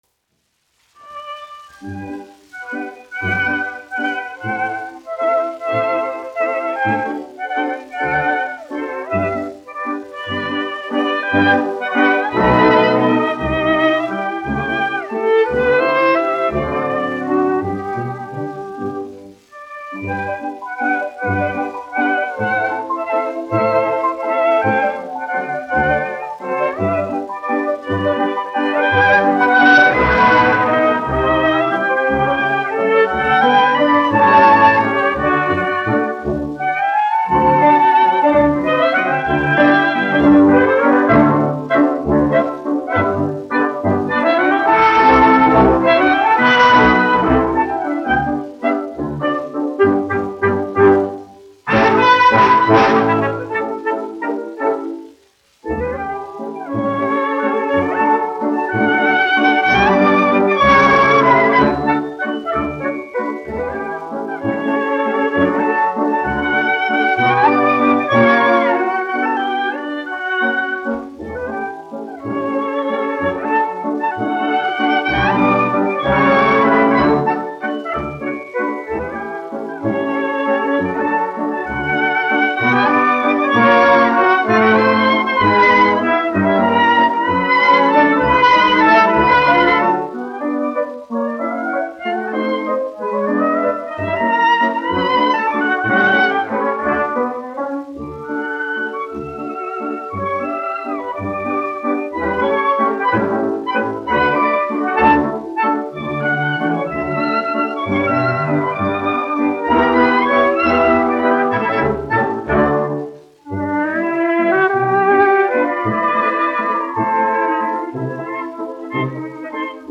1 skpl. : analogs, 78 apgr/min, mono ; 25 cm
Valši
Orķestra mūzika
Skaņuplate
Latvijas vēsturiskie šellaka skaņuplašu ieraksti (Kolekcija)